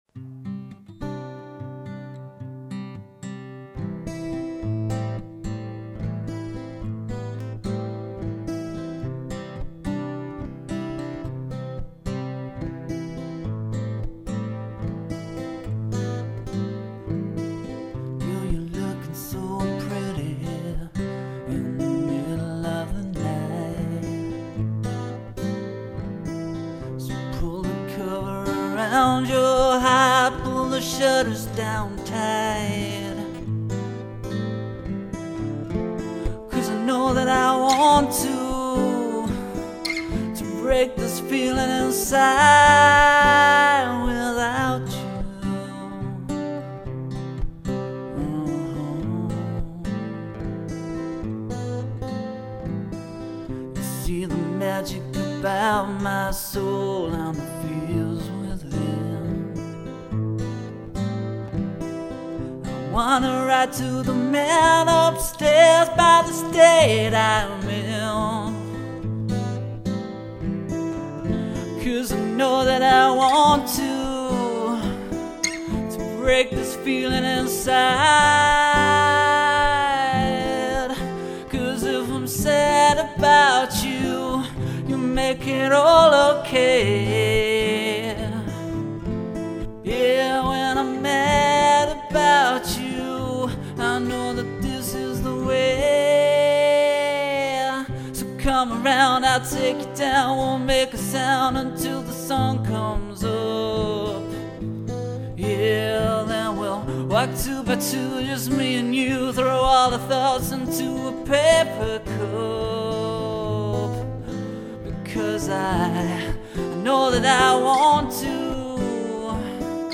Location: Langenbrücken, Germany